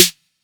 Snares
BASICSNR_2.wav